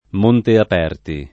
montap$rti] o Monteaperti [
monteap$rti] (antiq. Montaperto [montap$rto], Monte Aperti [m1nte ap$rti] e Monte Aperto [m1nte ap$rto]) top. (Tosc.) — Montaperto anche altre località (Camp., Sic.) — sim. i cogn. Montaperti, Montaperto